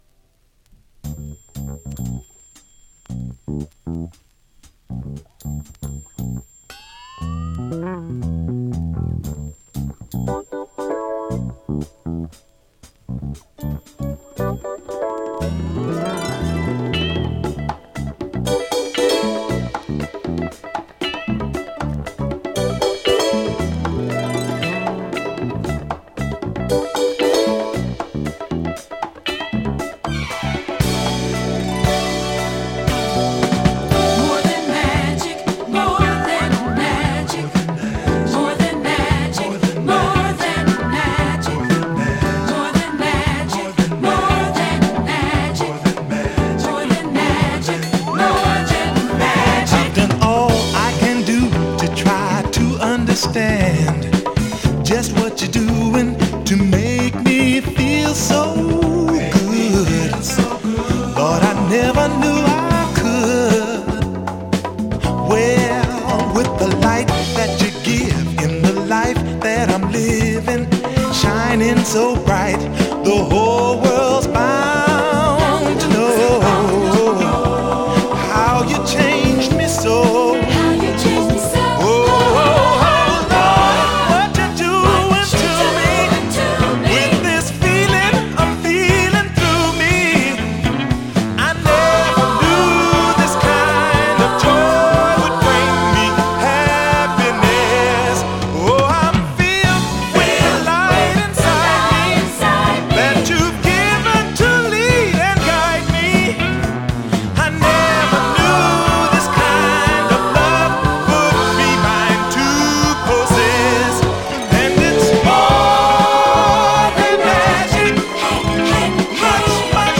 SOUL
GOSPEL MODERN SOUL〜JAZZY DANCER !…